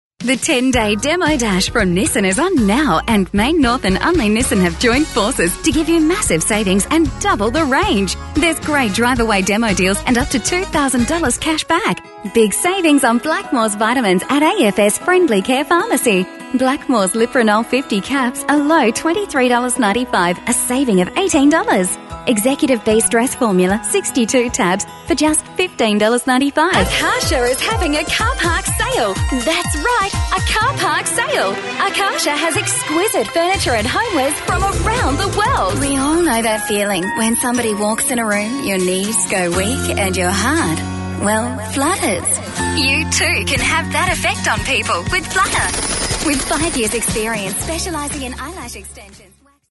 You will really hear the smile in this voice! Described as well spoken with a clear voice that cuts through, with the ability to sound young or mature, versatile yet believable.
Australian accent